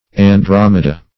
Andromeda \An*drom"e*da\, n. [L., fr. Gr.